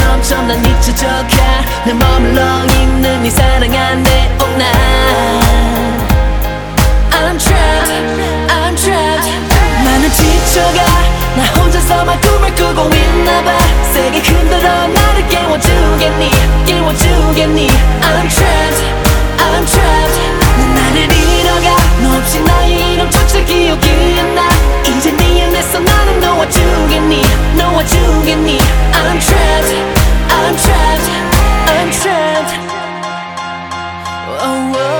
Жанр: Поп музыка
K-Pop